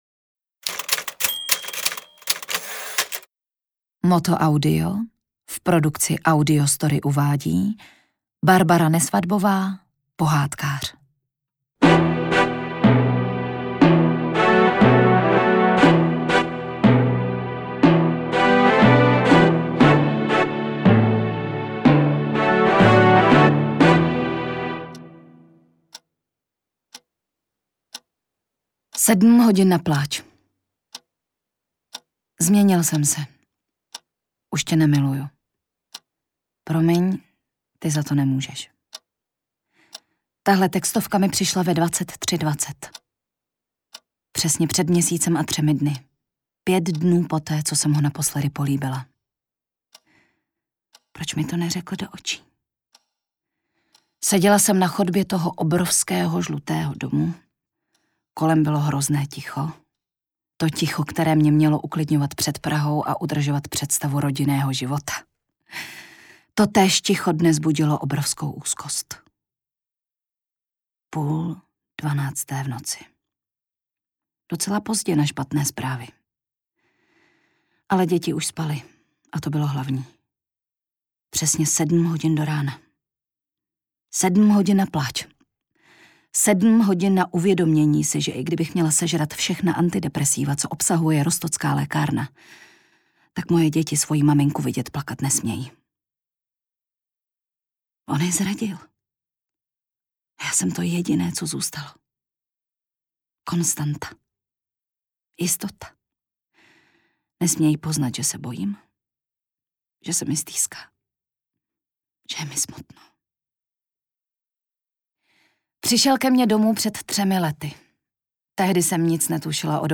Interpreti:  Tatiana Dyková, Dana Morávková
AudioKniha ke stažení, 66 x mp3, délka 4 hod. 52 min., velikost 667,1 MB, česky